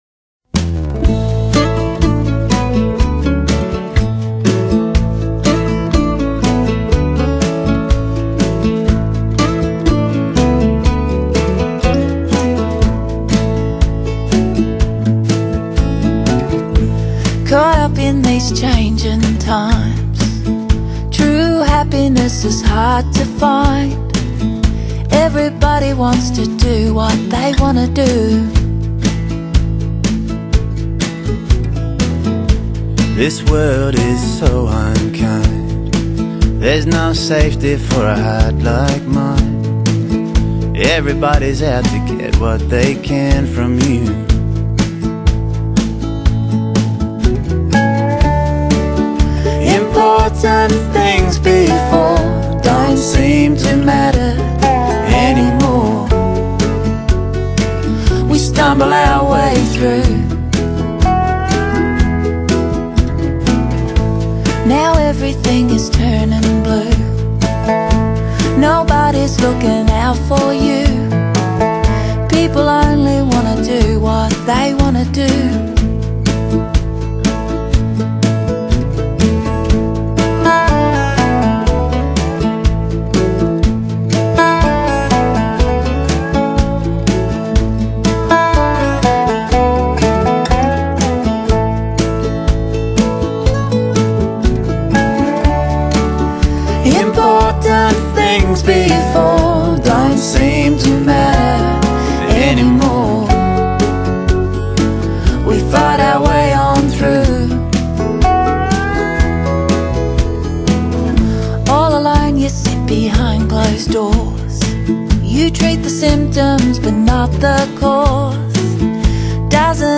【2004年獲澳洲鄉村音樂金吉他音樂獎歌手】